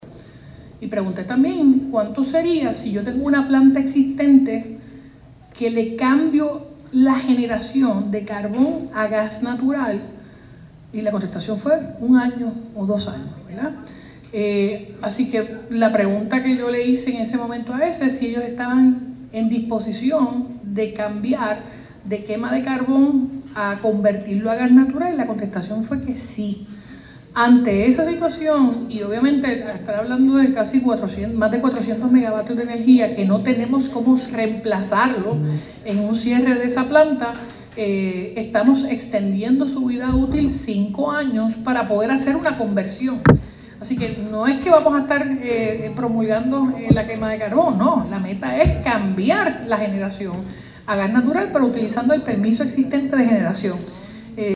Por eso estamos haciendo esto y no estamos esperando a que se nos cierre esa planta en el 2028, pero para poder sentarnos a negociar tenemos que tener un estatuto de ley porque ahora mismo ellos empezaban a cerrar algunas de esas unidades en mayo de este año, por eso la urgencia del proyecto”, dijo la gobernadora en conferencia de prensa.
238-JENNIFFER-GONZALEZ-GOBERNADORA-CAMBIARA-COMBUSTIBLE-EN-PLANTA-AES.mp3